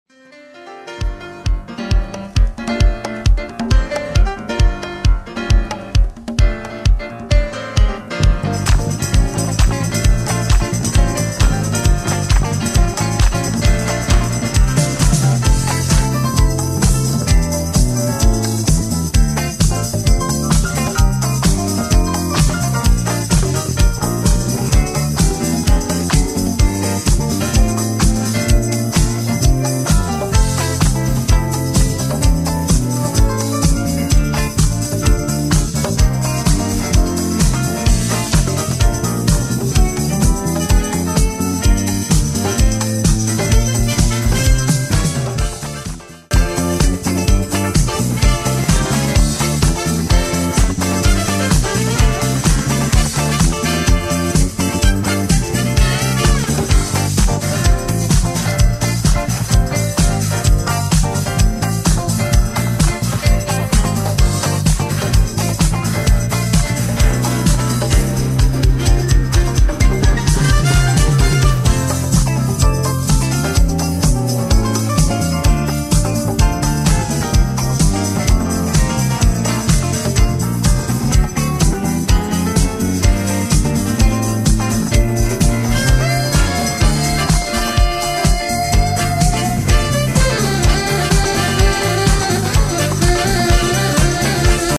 主に70sディスコ・ブギー路線のレア楽曲を捌いたエディット集となっています。
いずれもポジティヴなエネルギーで溢れた内容です！